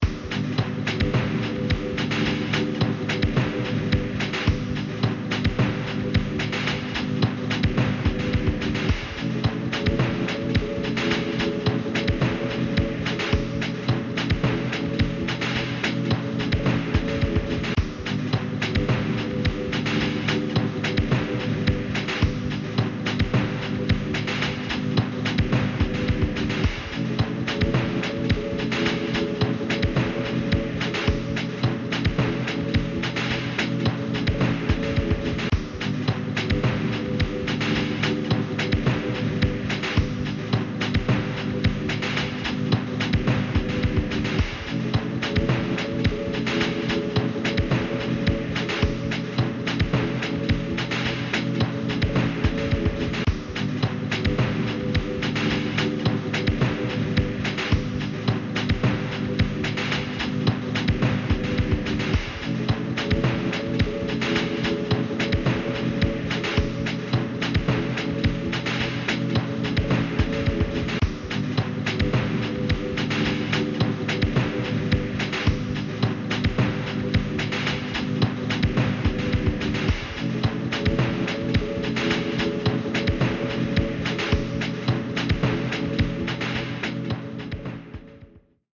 BGM
Speed 90%